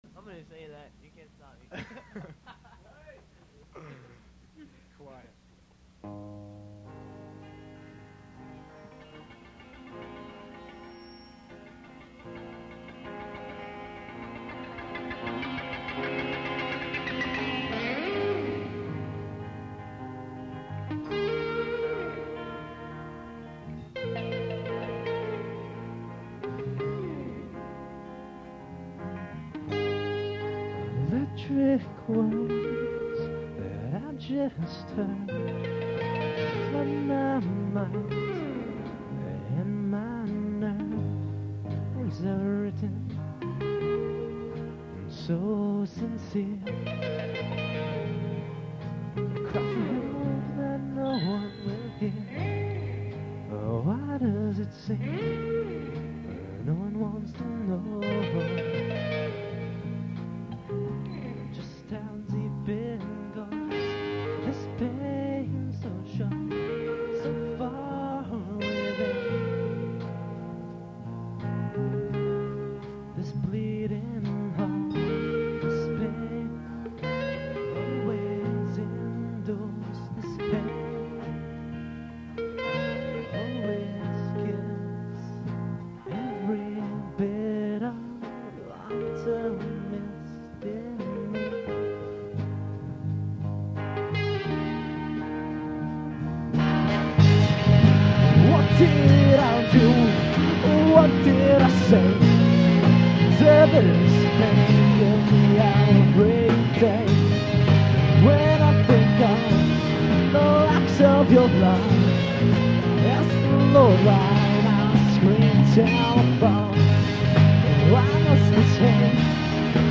we should have some more mp3's very soon...weve all been kind of busy lately so we havent had much time to do much recording...but we have some live stuff that should be up very very soon. these are live, all three. you can definately tell with "hey there"...because i couldnt stop laughing through my backing vocals ;)